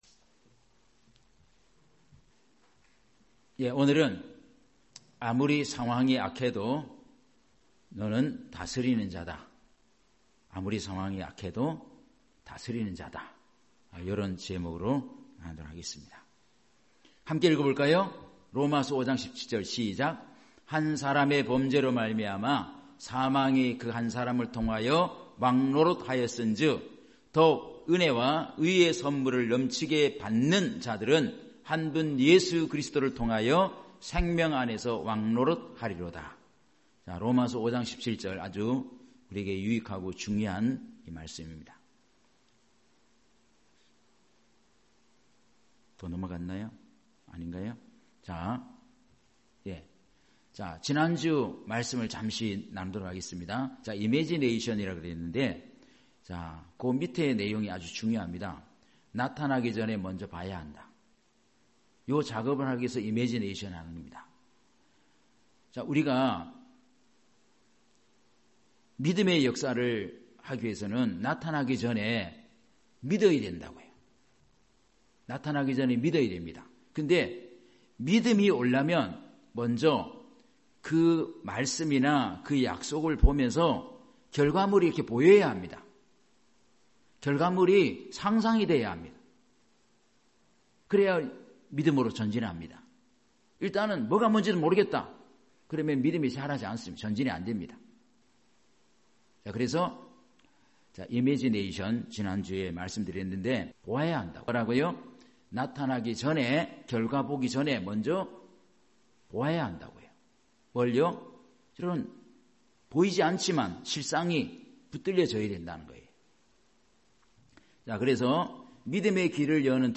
주일 오전 말씀 - 다스리는 자이다